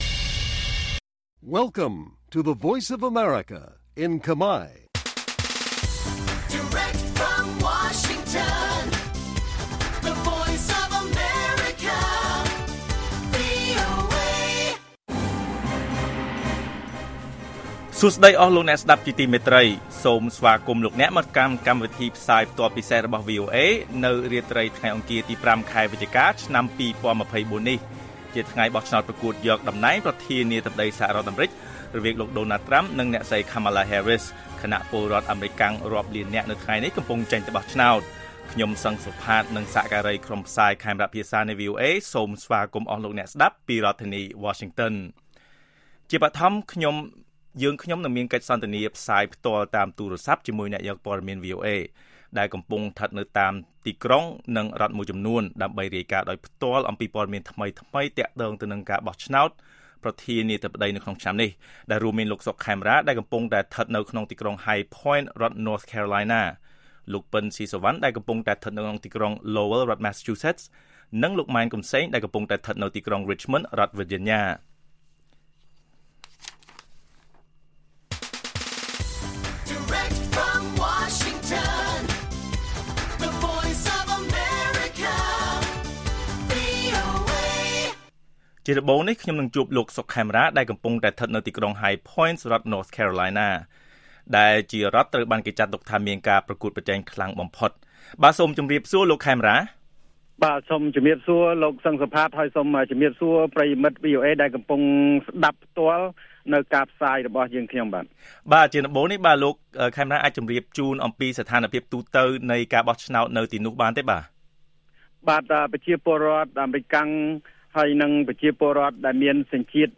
ព័ត៌មានពេលរាត្រី ៥ វិច្ឆិកា៖ កិច្ចសន្ទនាផ្ទាល់ជាមួយអ្នកយកព័ត៌មានវីអូអេស្តីពីការបោះឆ្នោតប្រធានាធិបតីសហរដ្ឋអាមេរិក២០២៤